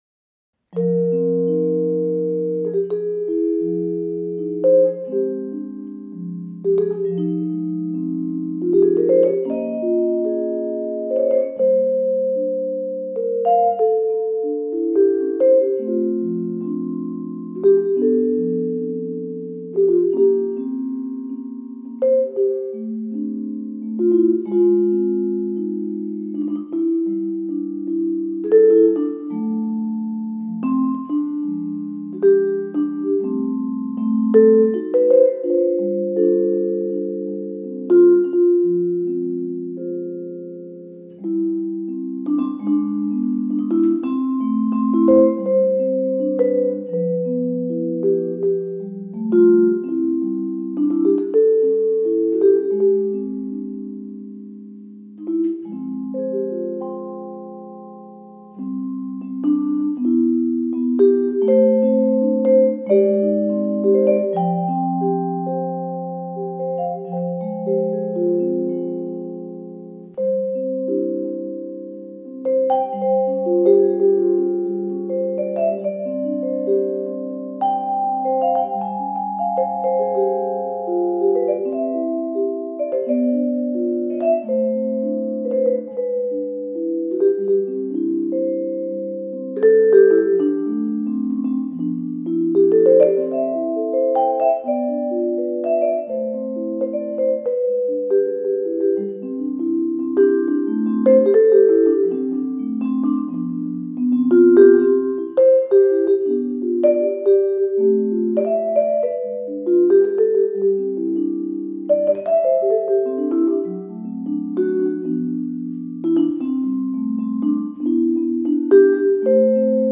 So lots of line cliché ideas